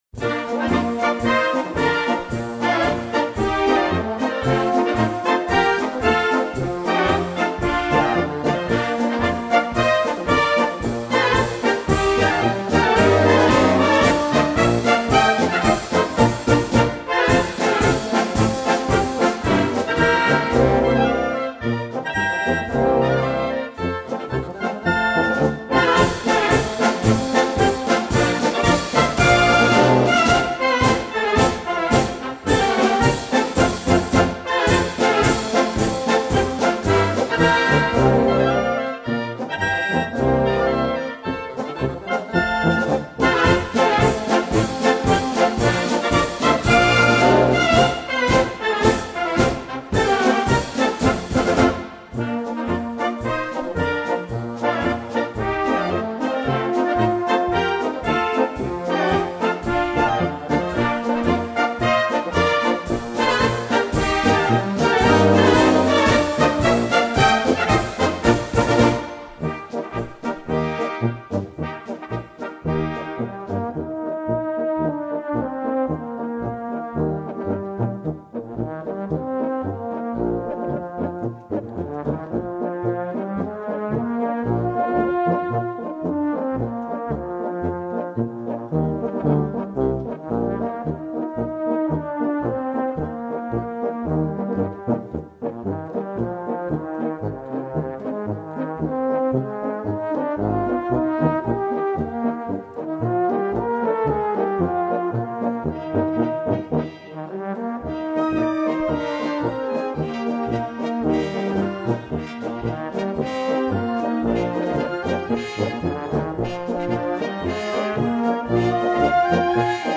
Gattung: Konzertmarsch
A4 Besetzung: Blasorchester PDF